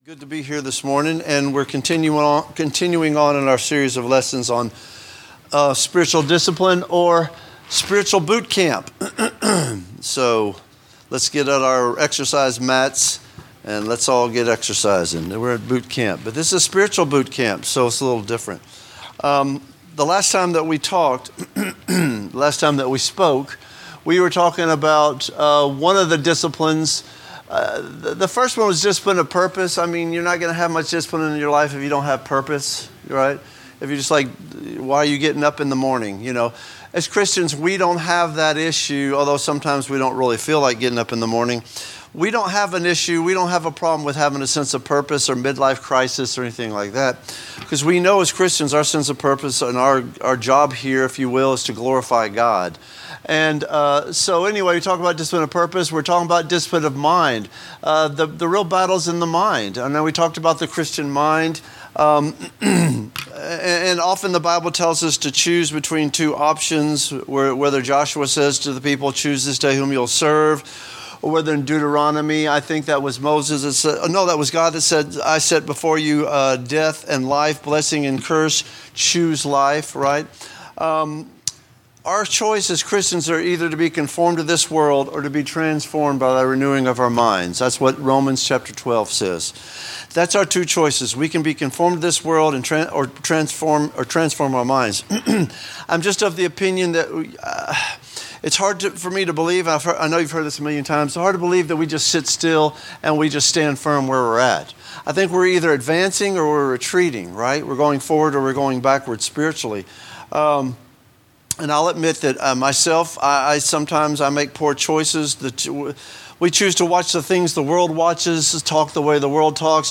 A message from the series "General Teaching."